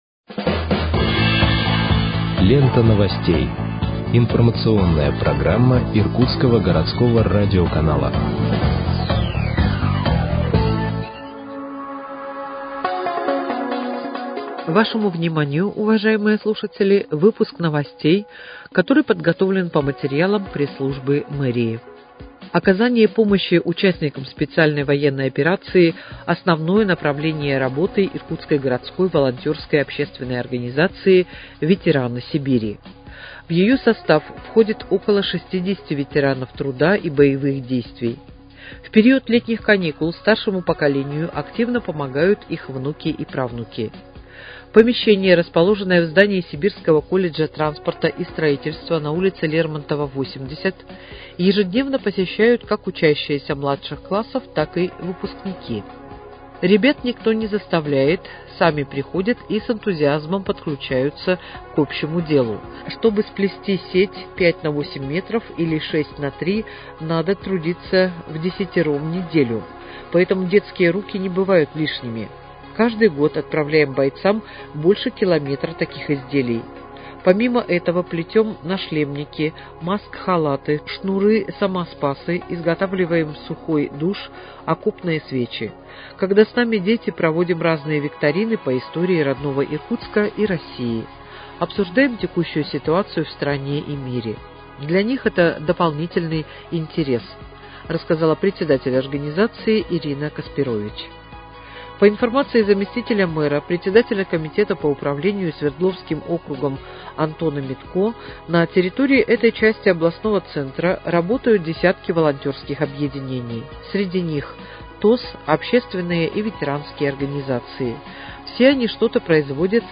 Выпуск новостей в подкастах газеты «Иркутск» от 12.08.2025 № 2